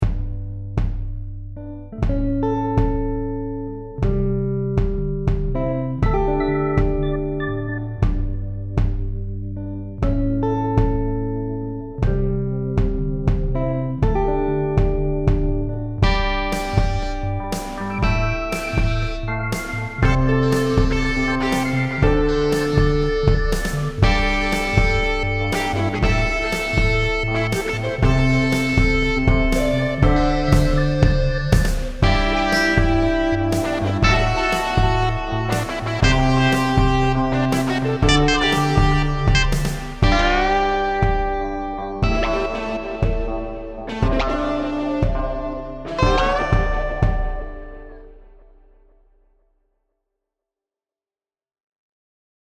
MP3 Demos: